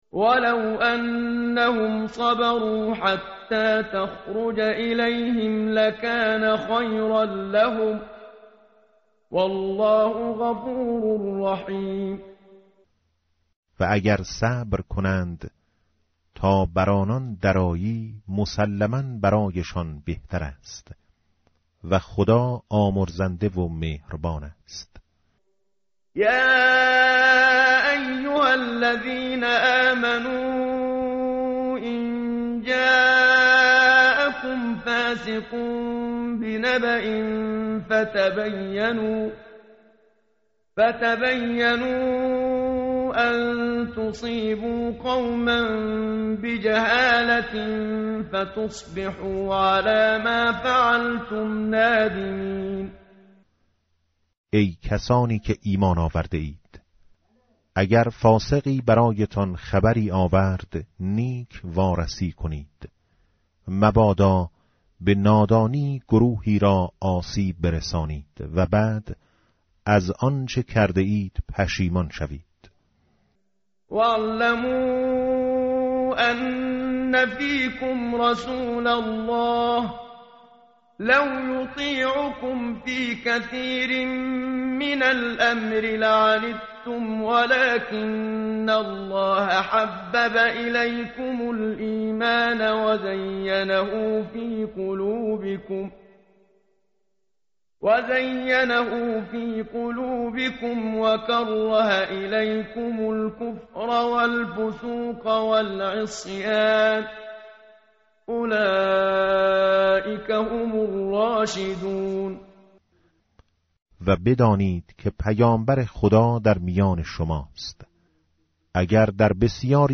tartil_menshavi va tarjome_Page_516.mp3